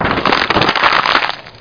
crinkle.mp3